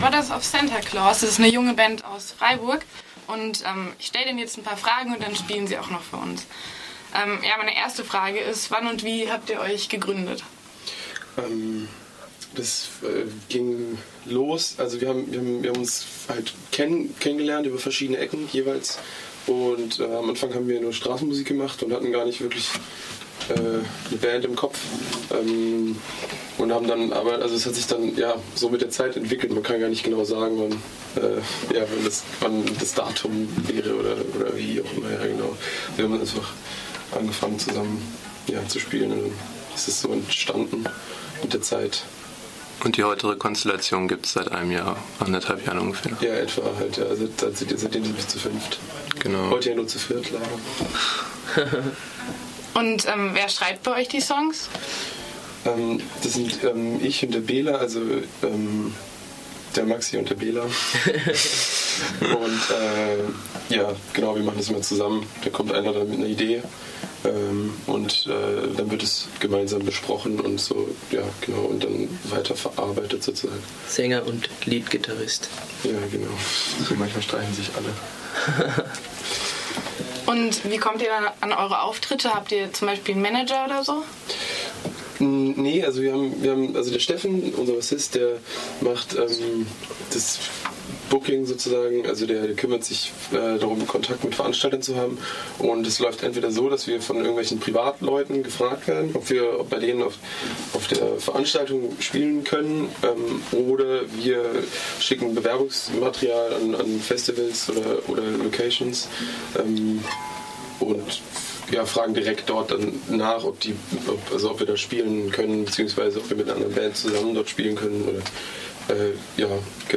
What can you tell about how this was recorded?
Am Ende des Interviews durften die RDL-HörerInnen eines ihrer Lieder Live hören.